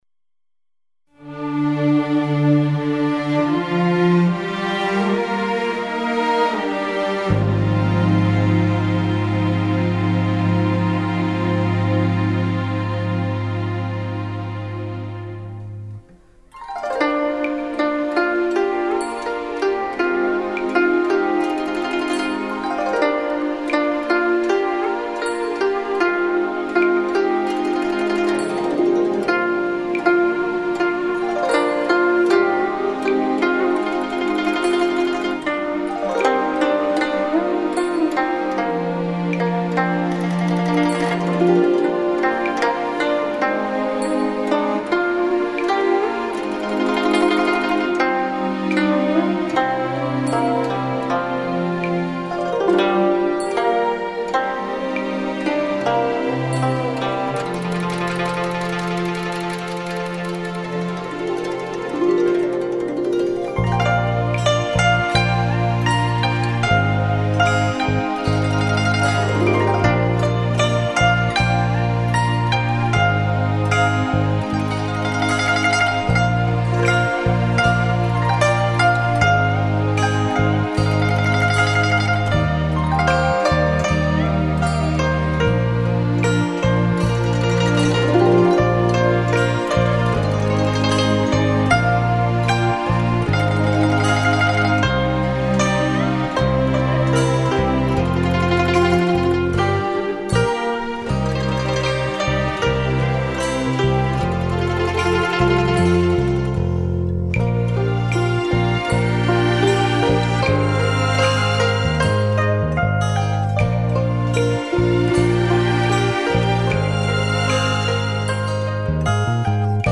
古筝 - 茉莉花.mp3